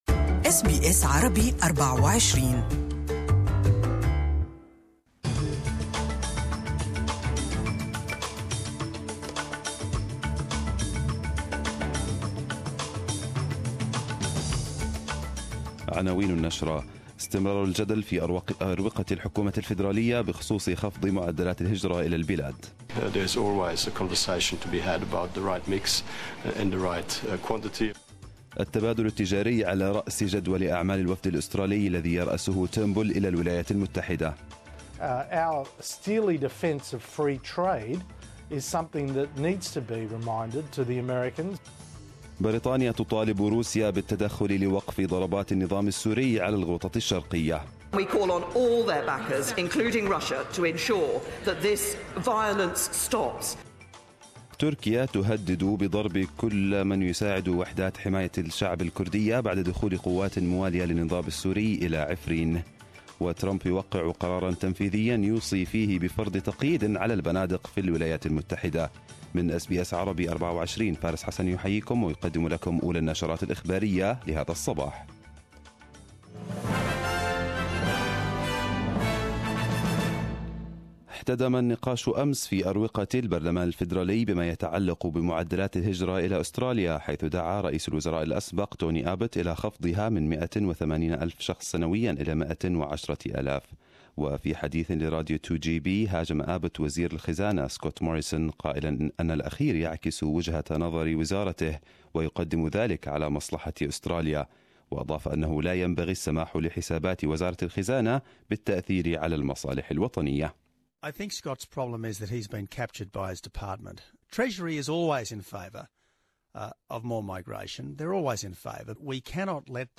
Arabic News Bulletin 22/02/2018